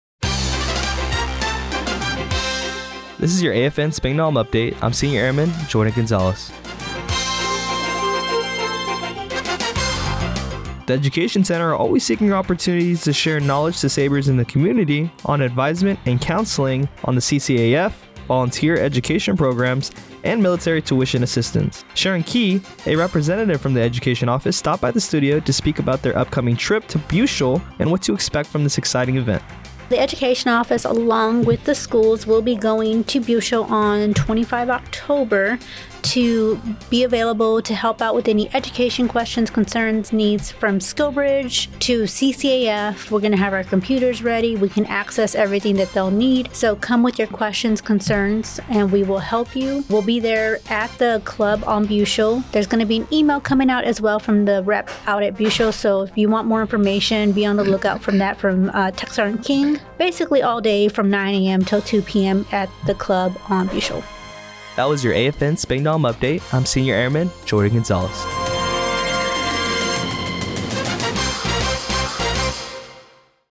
Radio news on 10-21-24 on Education Office.